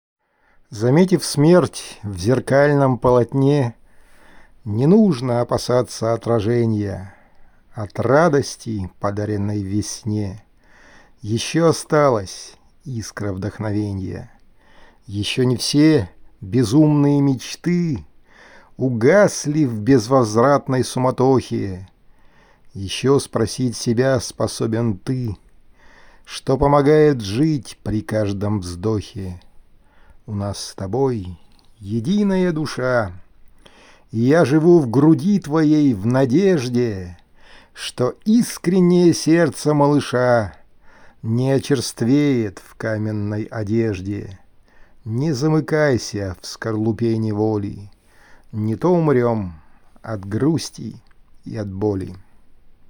Сонеты Шекспира: Декламация